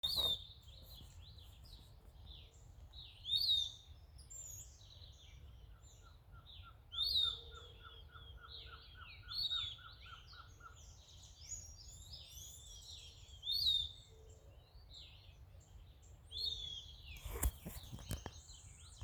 Bem-te-vi-pirata (Legatus leucophaius)
Nome em Inglês: Piratic Flycatcher
País: Argentina
Localidade ou área protegida: Reserva Privada San Sebastián de la Selva
Condição: Selvagem
Certeza: Fotografado, Gravado Vocal